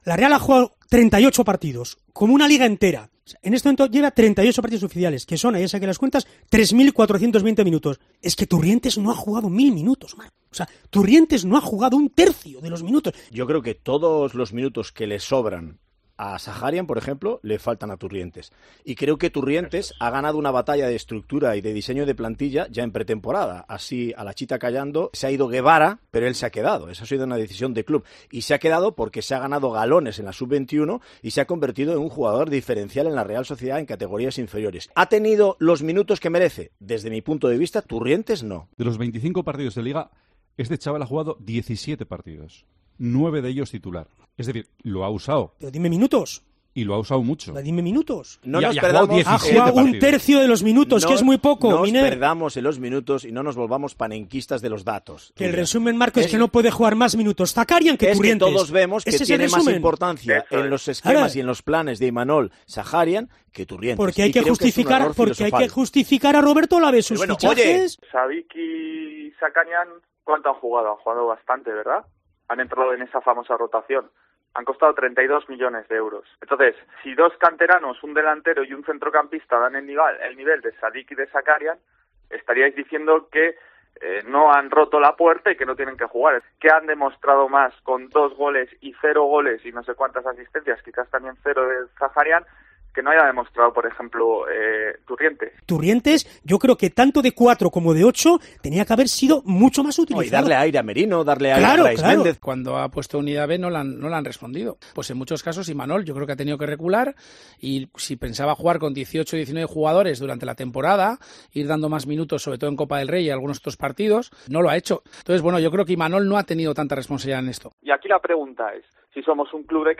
Debate en Deportes COPE Gipuzkoa sobre el papel de Beñat Turrientes en la Real Sociedad
Los tertulianos de Deportes COPE Gipuzkoa discuten en Tiempo de Txoko sobre el papel del canterano Beñat Turrientes en la Real Sociedad de Imanol Alguacil.